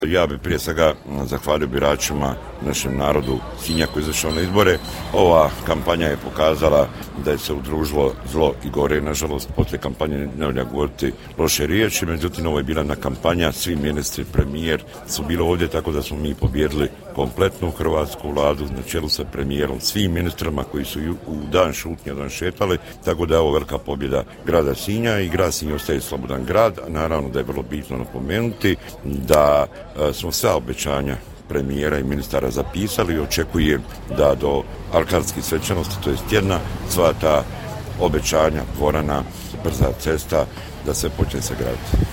Poslušajte što je za rezultate kazao Miro Bulj: